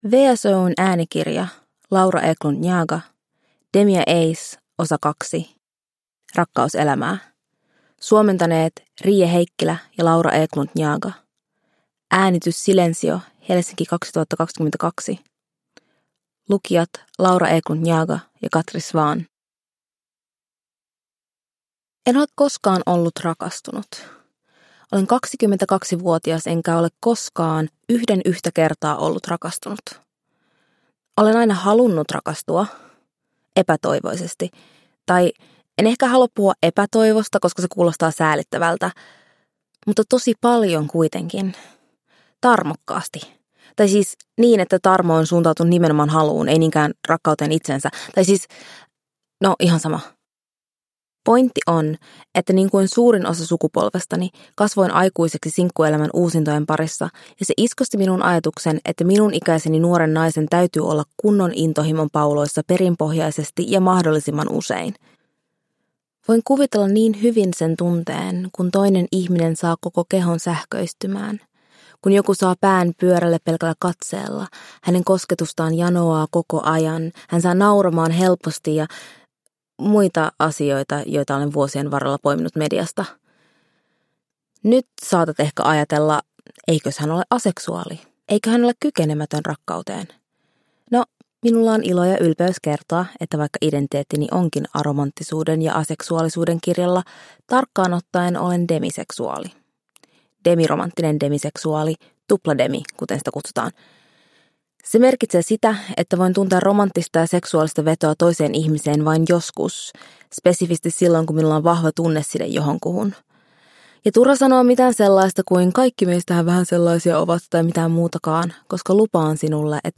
Demi & Ace 2: Rakkauselämää – Ljudbok – Laddas ner